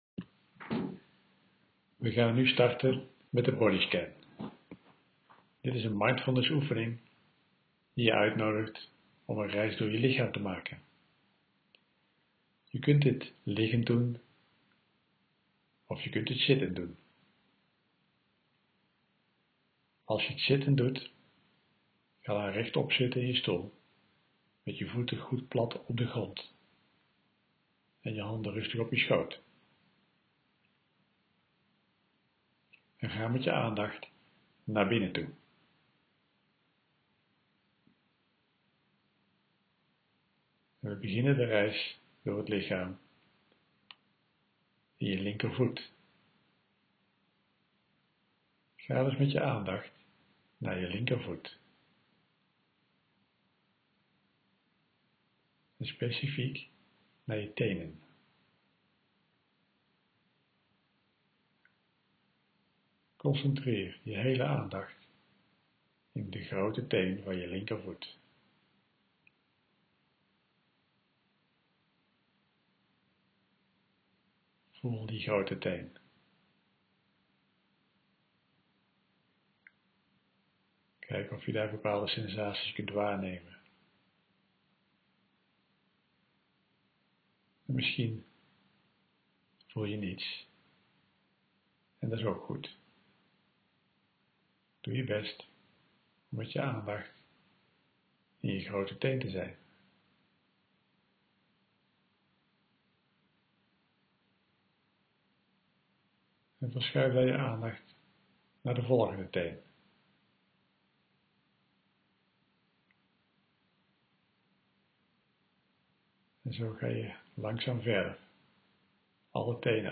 Bodyscan.mp3